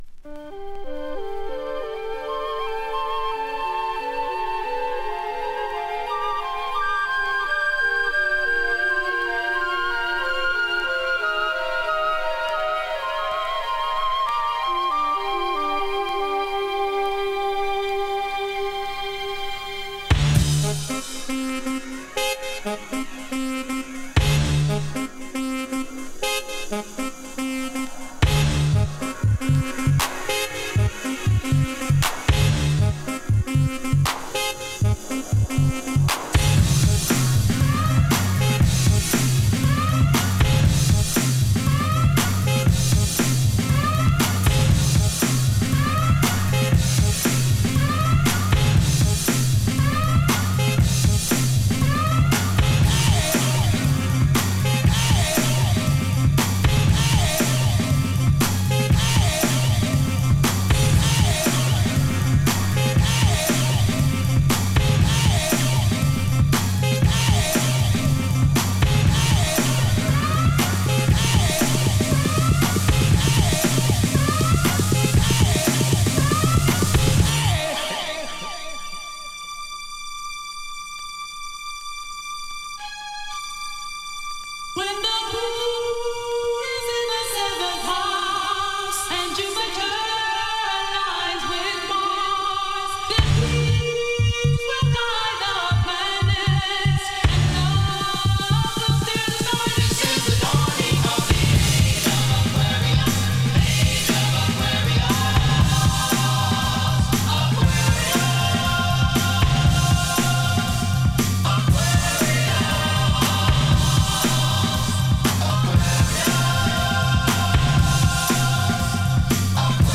をメドレー形式でハウスミックス！